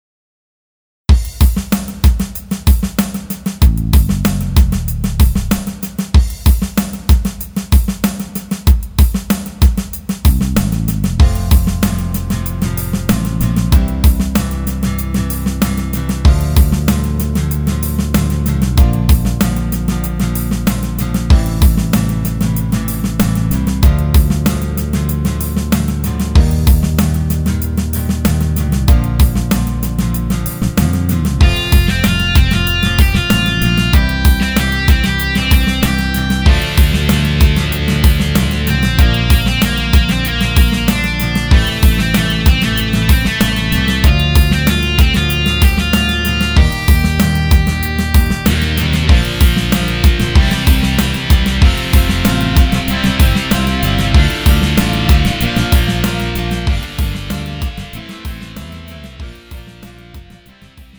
음정 (남자)
장르 가요 구분 Lite MR